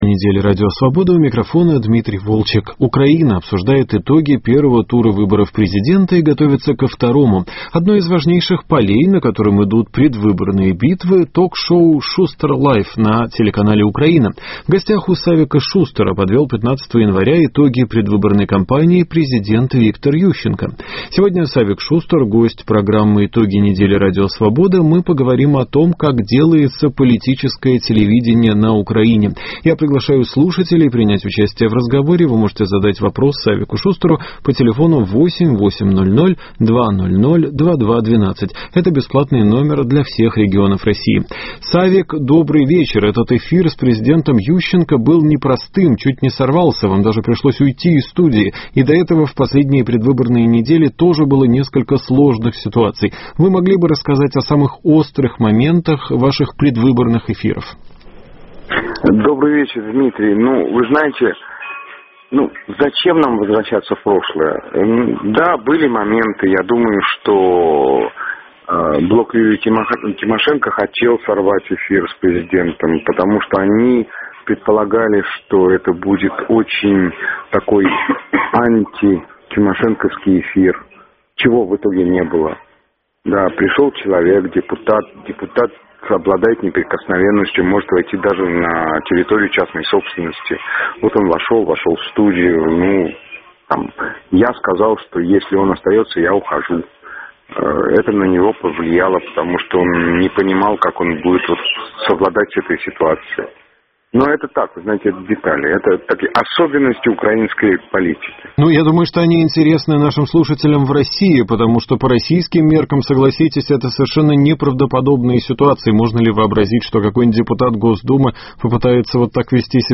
Гость программы – телеведущий Савик Шустер